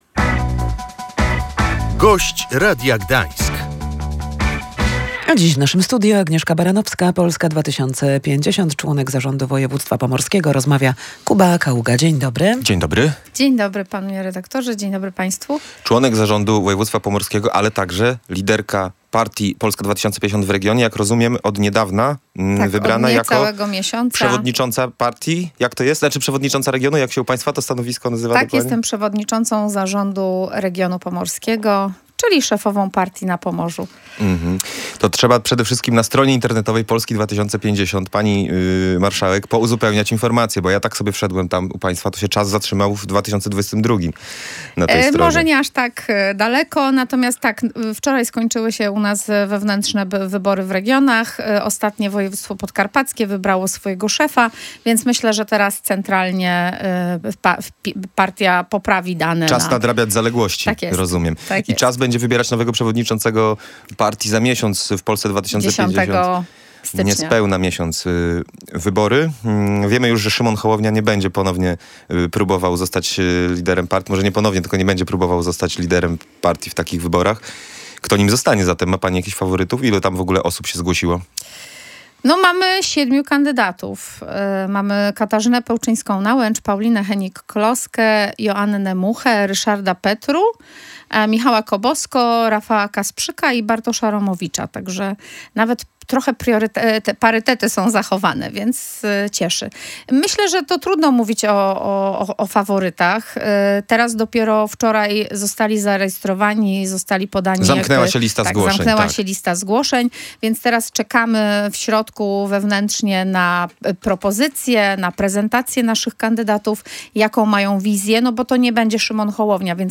Problemy psychiczne dotykają coraz większej liczby osób. Zarząd województwa pomorskiego stara się poprawić sytuację psychiatrii w regionie – wskazywała w Radiu Gdańsk członkini zarządu i szefowa Polski 2050 na Pomorzu Agnieszka Baranowska.
Jak zaznaczyła w audycji „Gość Radia Gdańsk”, trwają rozmowy dotyczące kształcenia medyków.